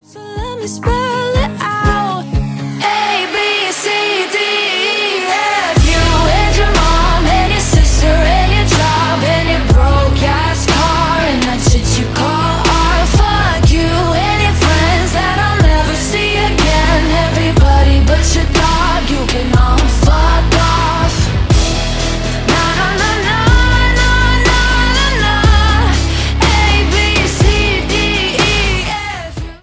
Stereo
Поп
громкие